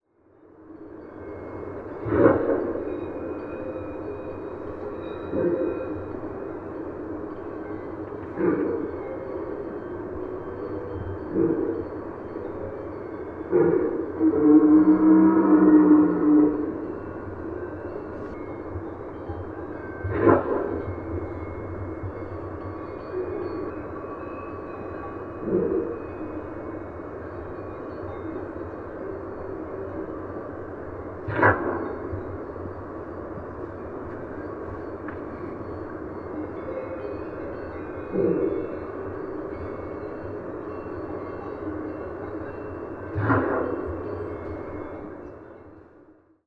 Pour enregistrer les sons du brame, j’ai utilisé un microphone parabolique très sensible, veillant à garder une distance de sécurité pour éviter de perturber les rituels d’accouplement.
Brame-du-cerf-3.mp3